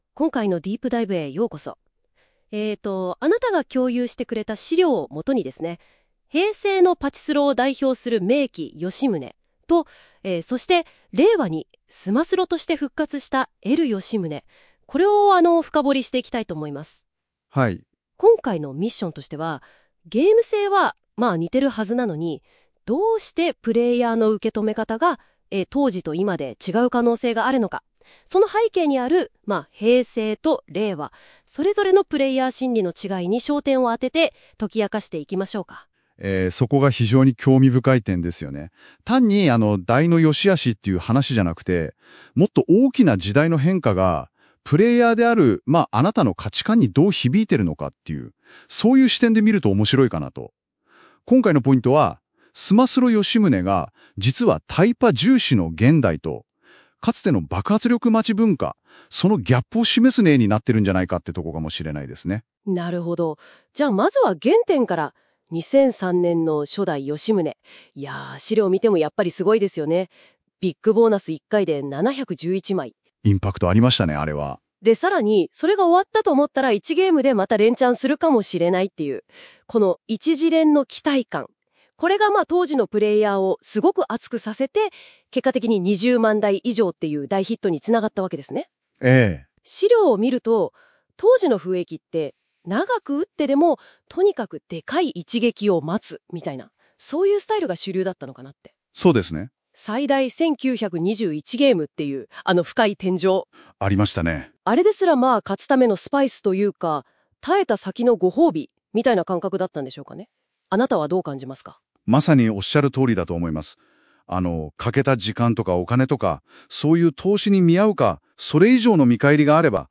※AIで生成／試験運用中